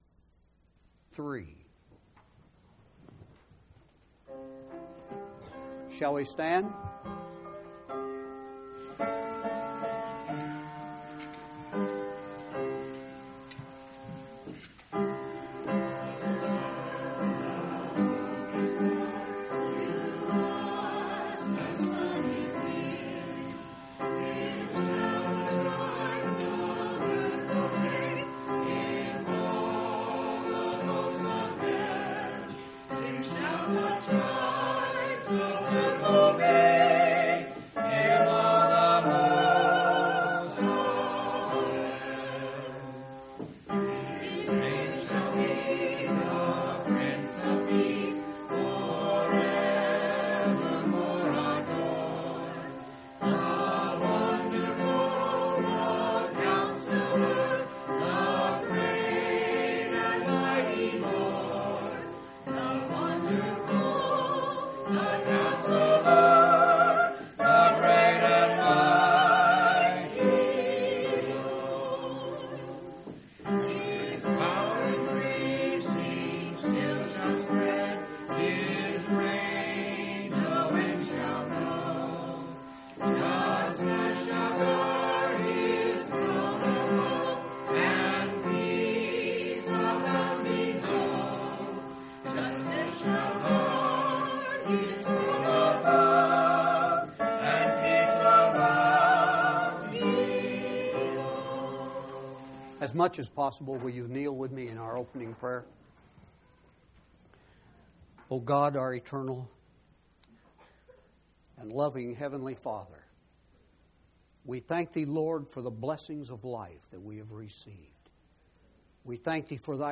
12/26/1999 Location: Phoenix Local Event